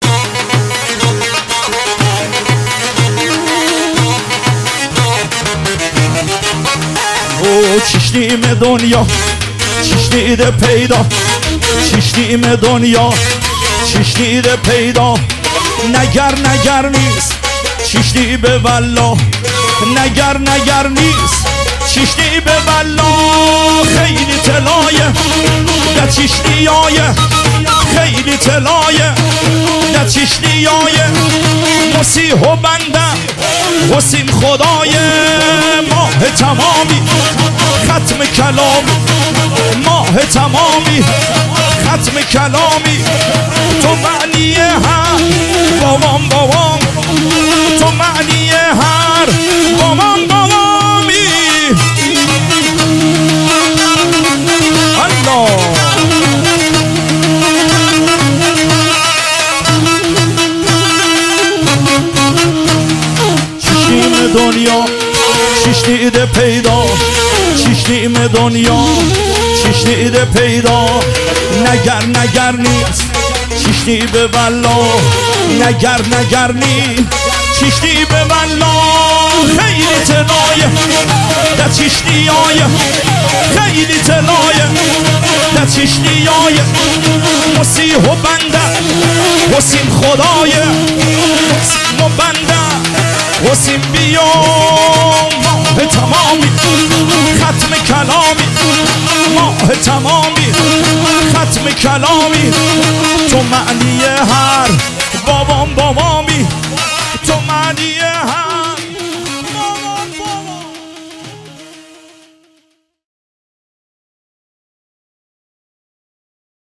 عاشقانه محلی لری عروسی